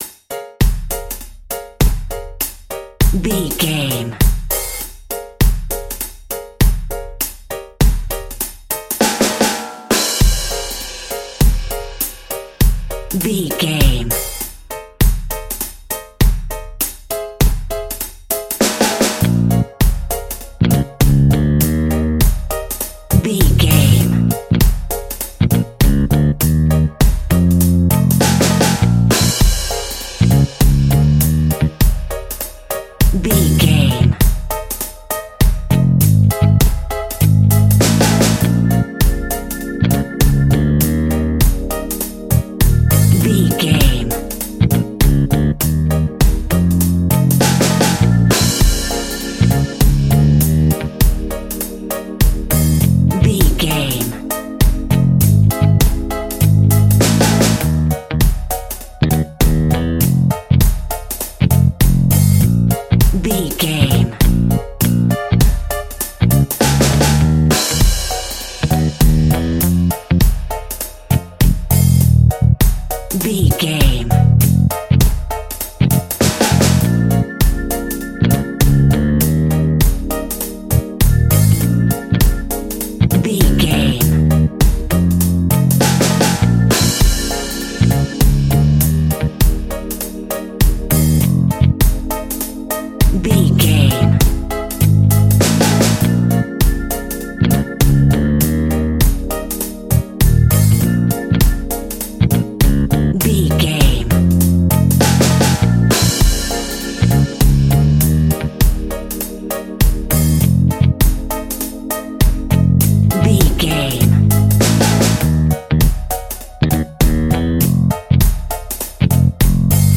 Aeolian/Minor
B♭
dub
laid back
chilled
off beat
drums
skank guitar
hammond organ
transistor guitar
percussion
horns